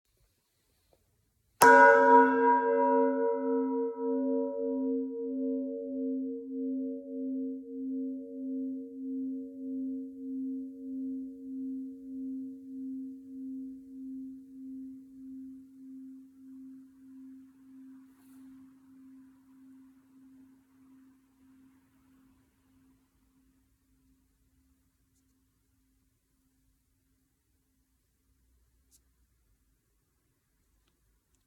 Die verschiedenen Teiltöne der Glocken hört man an allen Anschlagspunkten, jedoch in jeweils unterschiedlicher Intensität.
Anschlagpunkt a [582 KB]
glocke-brauweiler-a.mp3